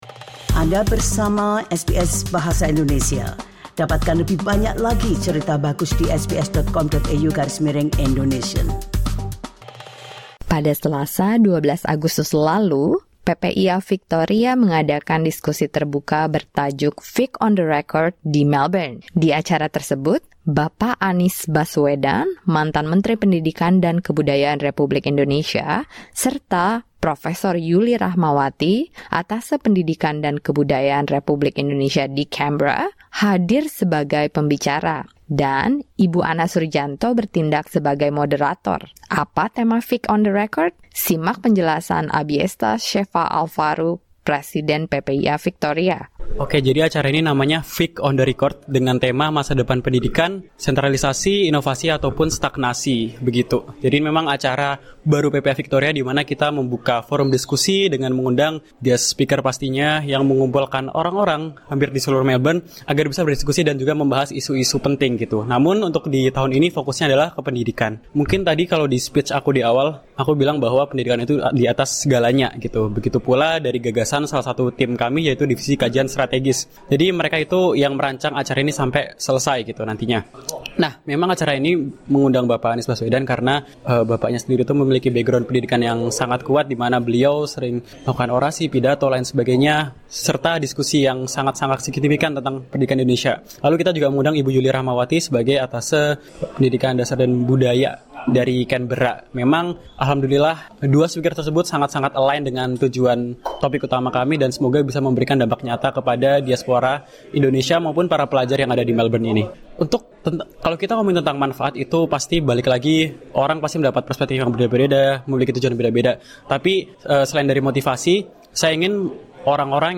Dalam diskusi terbuka yang diadakan oleh PPIA Victoria di Melbourne, mantan Menteri Pendidikan dan Kebudayaan Indonesia, Anies Baswedan, mengatakan bahwa guru adalah kunci utama pendidikan di Indonesia.